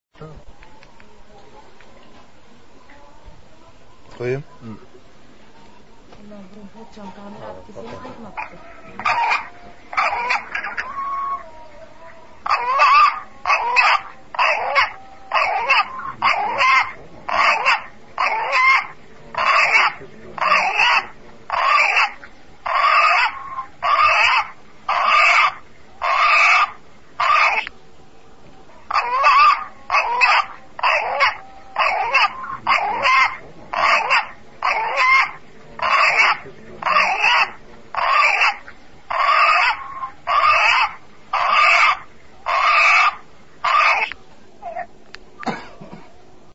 В киргизском Ош появился петух который кричит "Аллах, Аллах!". Причем петух это делает каждый день.
Подлинность записи гарантирует редакция "Фергана.РУ", которая предлагает прослушать оригинальное пение птицы, а также просит ученых объяснить сей феномен.